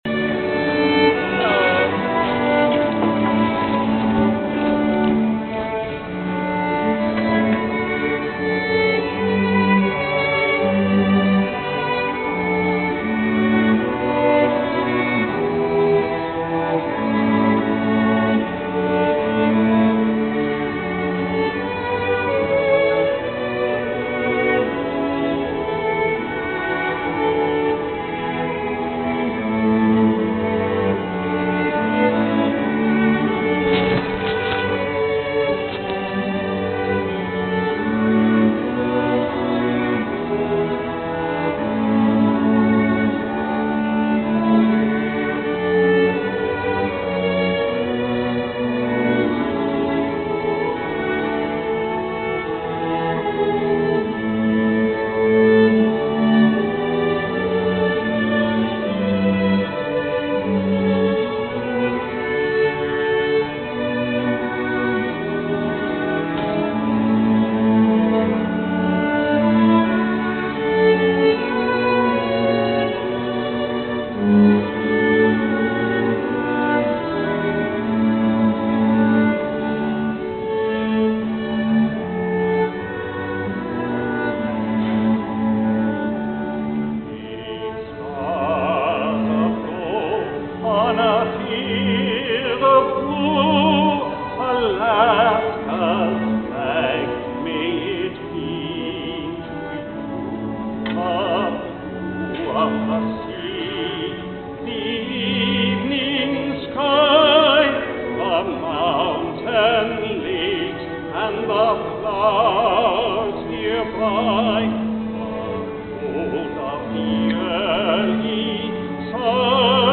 Alaska Flag Song. This is a recording of me singing Alaska’s state song, The Alaska Flag Song, accompanied by my daughter’s high school string quartet, on November 8, 2005 at a celebration at the Anchorage Museum of the fiftieth anniversary of the Alaska Constitutional Convention.